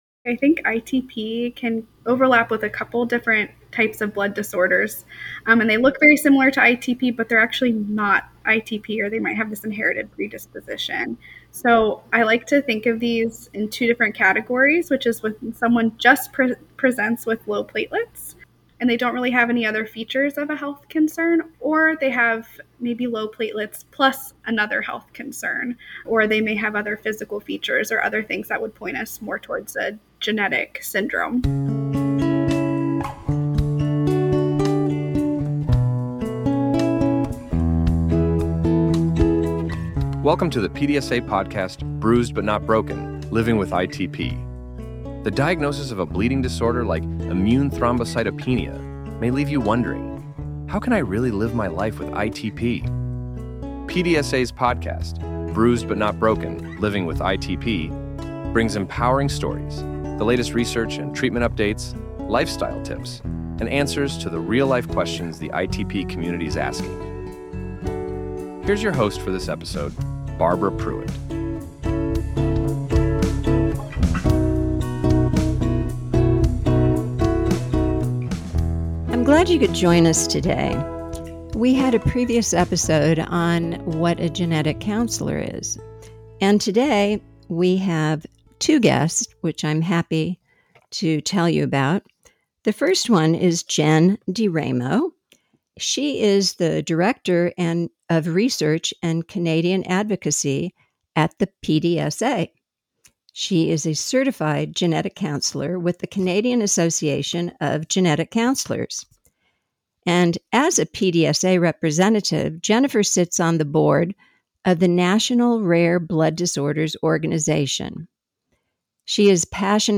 Whether you’re curious about your family history or simply want to understand the science behind genetic testing, this conversation offers valuable takeaways for anyone living with or caring for someone with ITP.